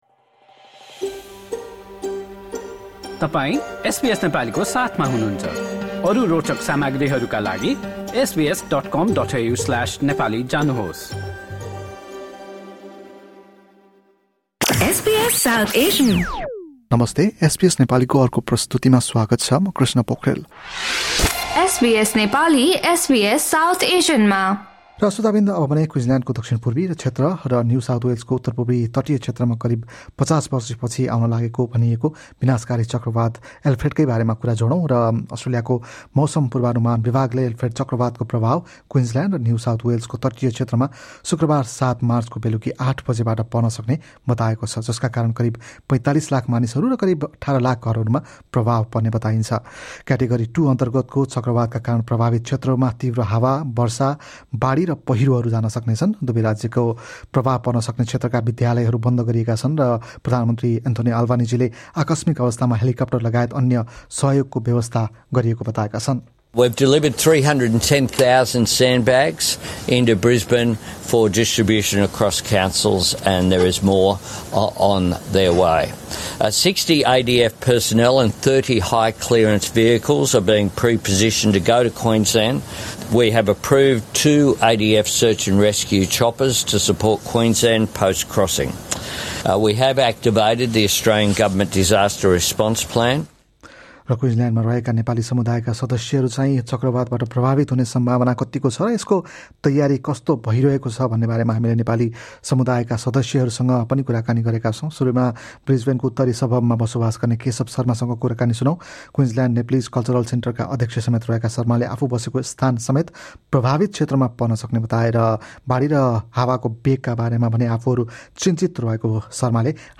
एसबीएस नेपालीसँग गरेको कुराकानी सुन्नुहोस्।